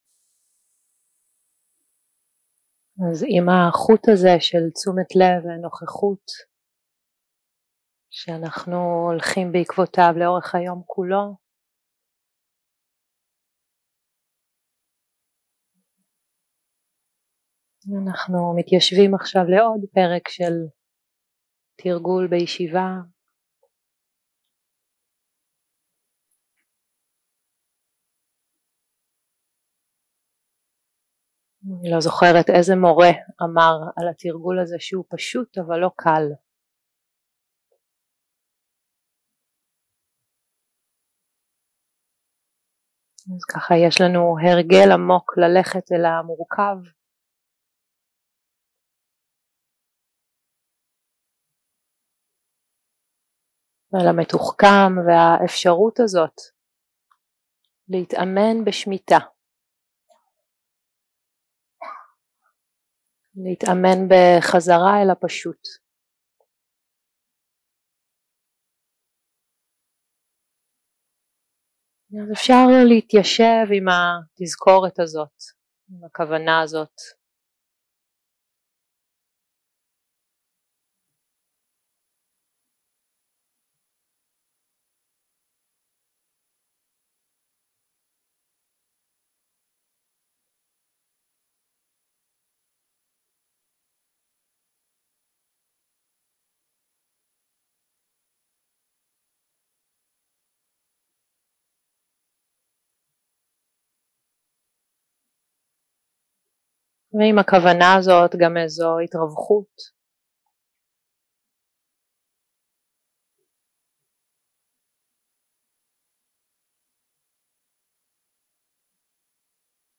יום 2 – הקלטה 2 – צהריים – מדיטציה מונחית
Guided meditation